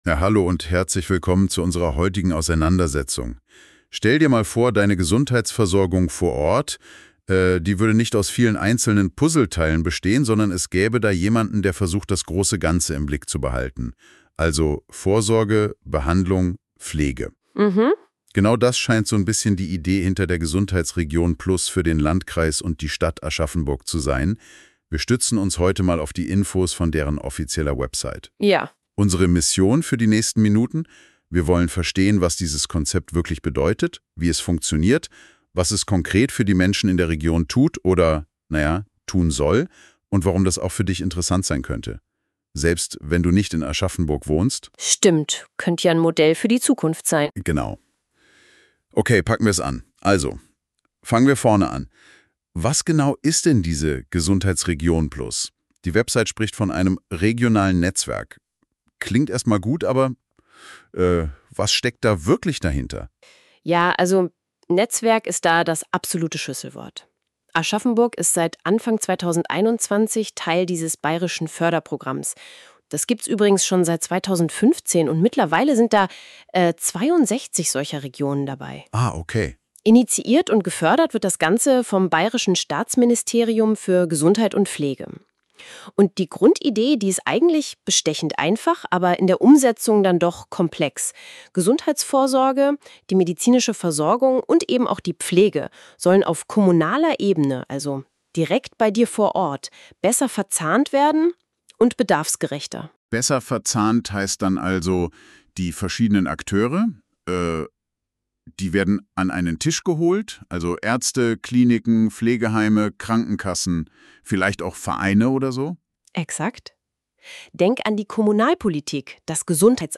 Ganz einfach: Wir lassen es eine Künstliche Intelligenz erklären!